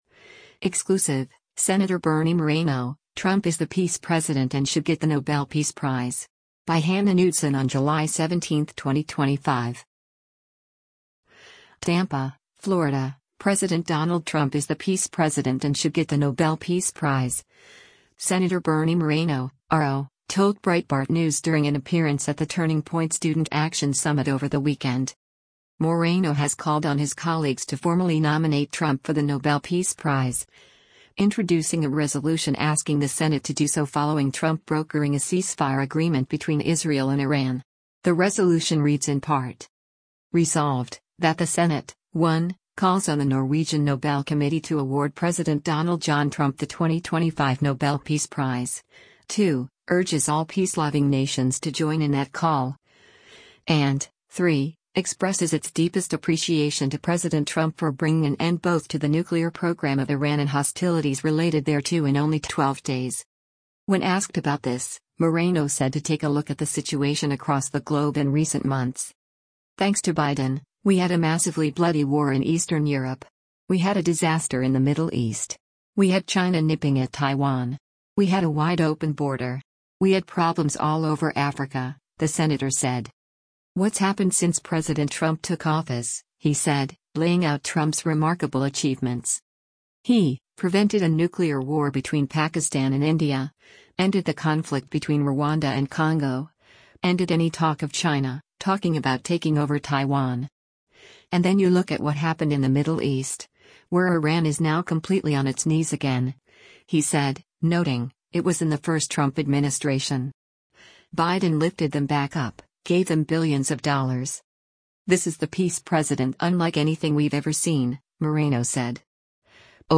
TAMPA, Florida — President Donald Trump is the “peace president” and should get the Nobel Peace Prize, Sen. Bernie Moreno (R-OH) told Breitbart News during an appearance at the Turning Point Student Action Summit over the weekend.